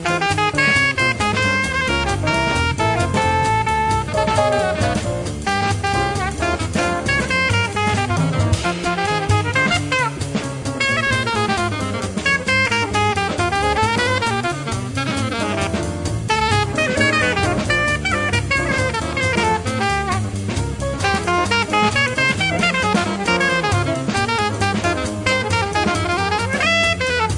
The Best In British Jazz
Recorded Premises Studios, London December 7-9th 1993